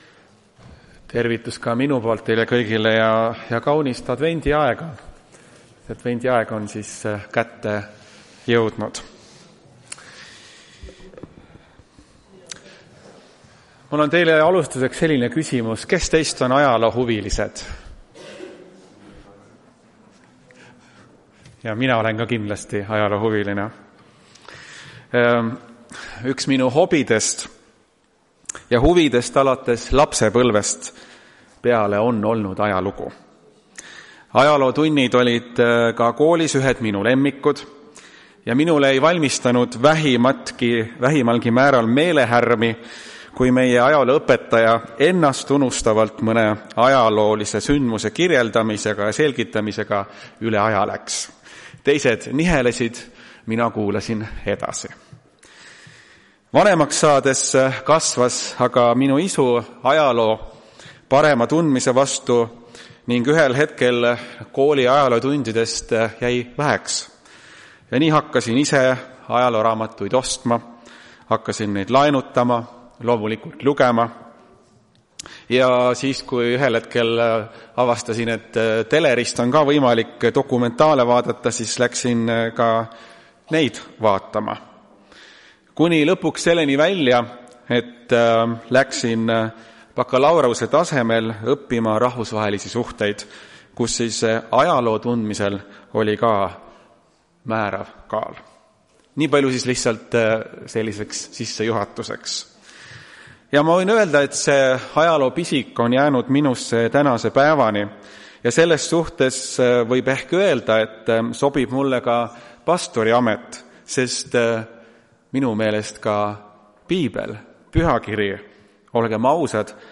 Jutlused
Tartu adventkoguduse 06.12.2025 teenistuse jutluse helisalvestis.